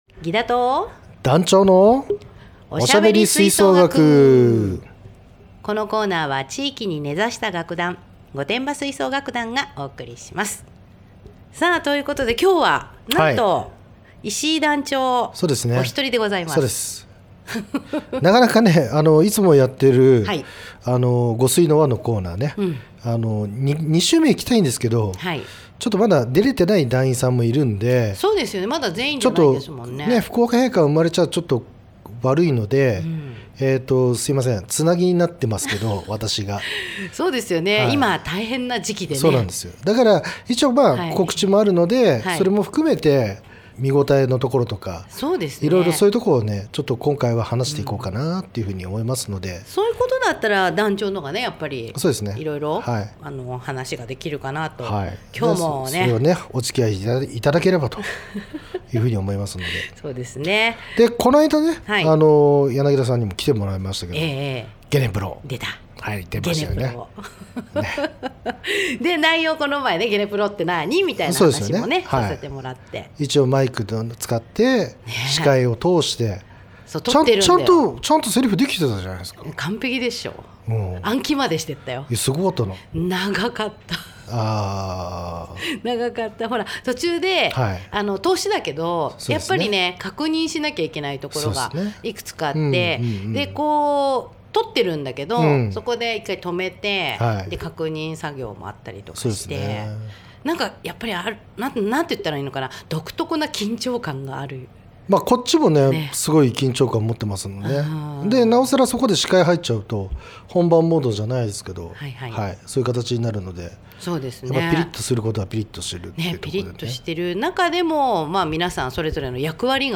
著作権の都合上BGMはカットしてあります